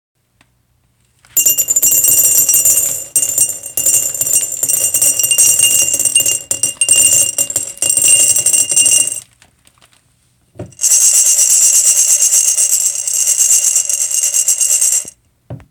Sound of jellybeans being poured into a jar and the briefly shaken while still in the jar.
This entry was posted in Field Recording .
Jellybeans.mp3